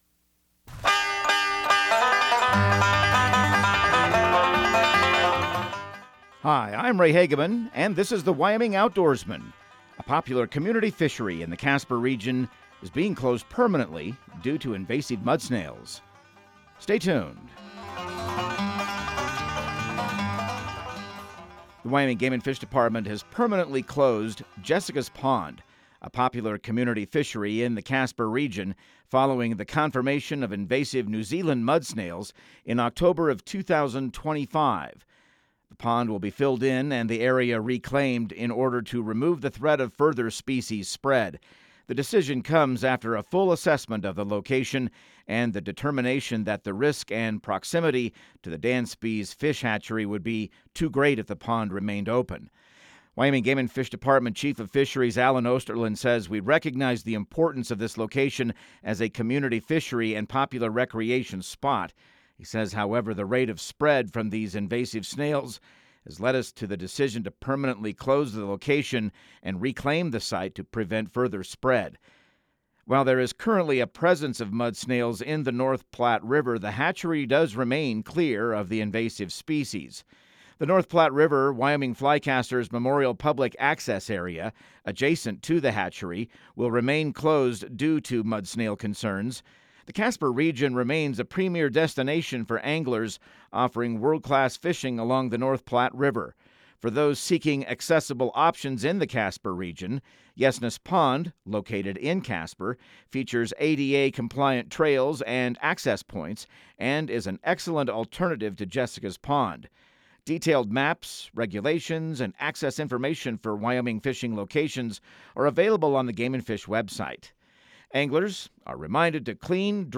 Radio news | Week of March 2